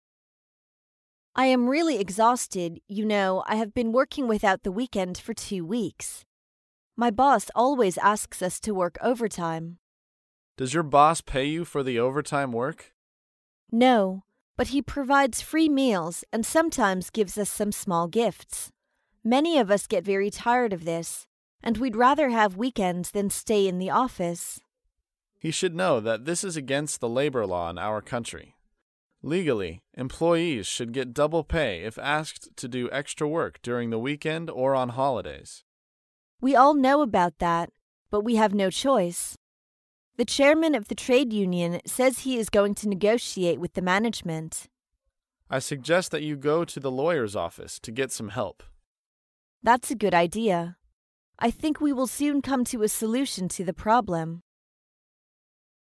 在线英语听力室高频英语口语对话 第137期:抱怨加班的听力文件下载,《高频英语口语对话》栏目包含了日常生活中经常使用的英语情景对话，是学习英语口语，能够帮助英语爱好者在听英语对话的过程中，积累英语口语习语知识，提高英语听说水平，并通过栏目中的中英文字幕和音频MP3文件，提高英语语感。